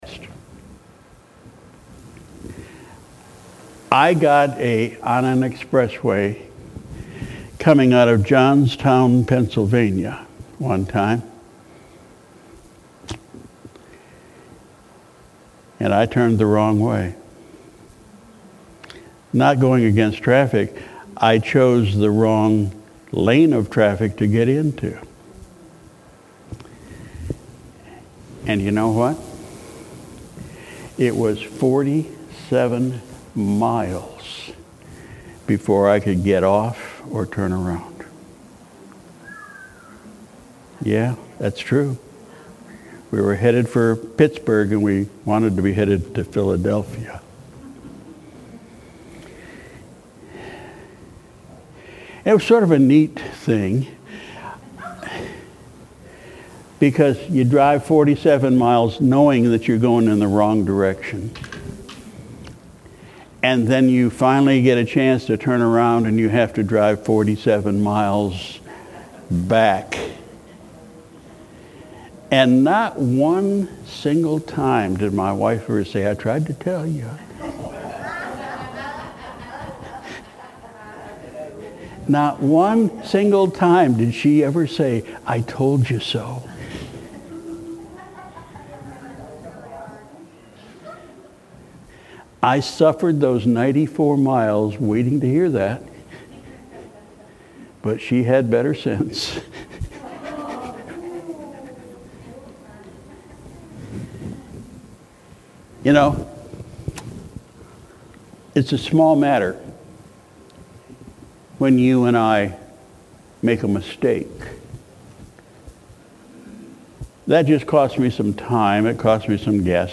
Sunday, August 26, 2018 – Morning Service